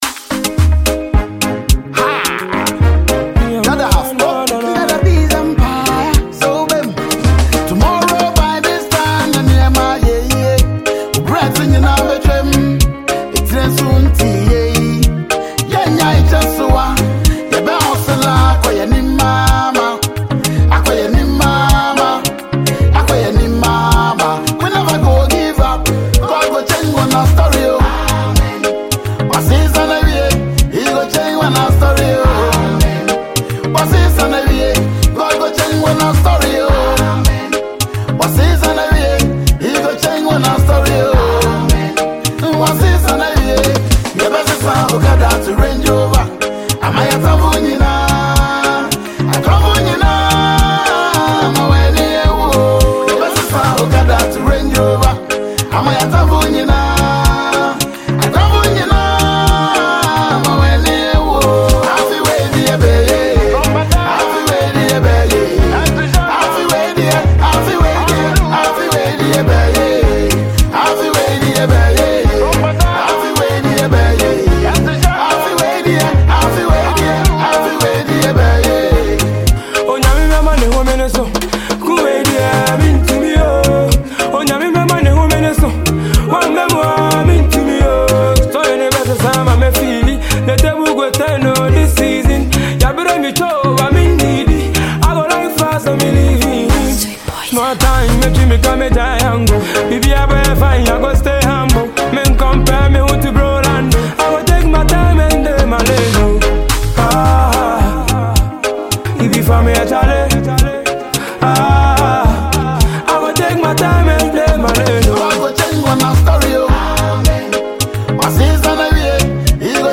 Highlife singer/songwriter